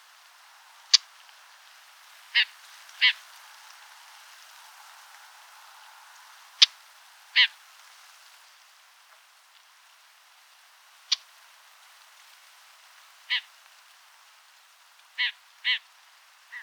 Lanius collurio - Red-backed shrike - Averla piccola
E 28°28' - ALTITUDE: 100 m. - VOCALIZATION TYPE: two different call types.
Again a clear mimicry of sparrow call (check the previous recording). Background: wind noise.